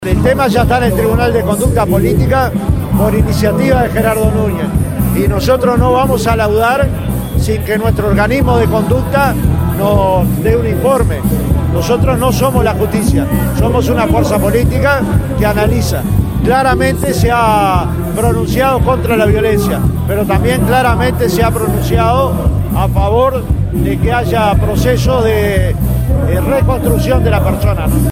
Nosotros no vamos a laudar sin que nuestro organismo de conducta nos de una informe”, dijo Pereira en rueda de prensa.